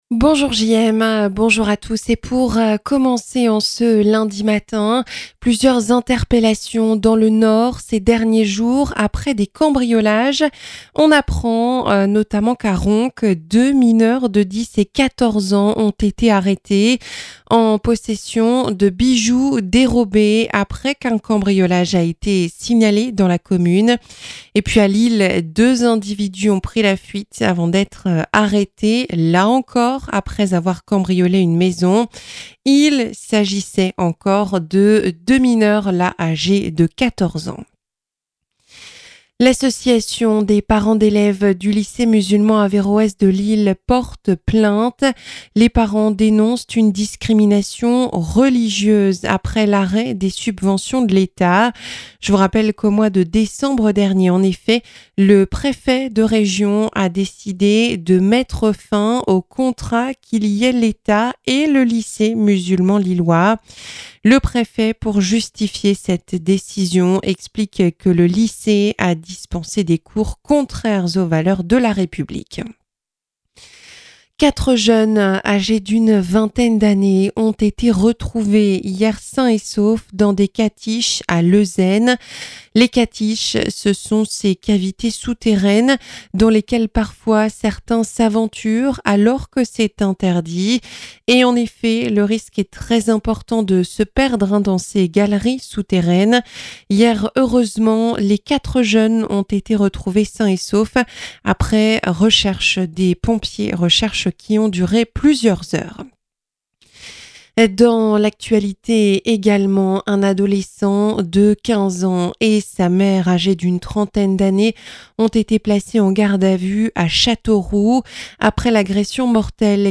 Journal 7h - Plusieurs mineurs interpellés dans le Nord pour des cambriolages